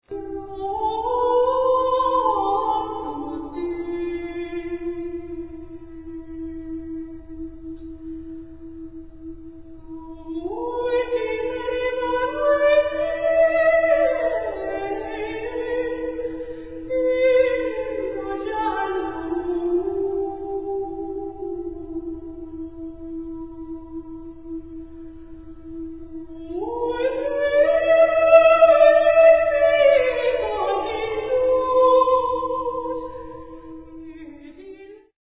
soprano
response for the Holy Innocents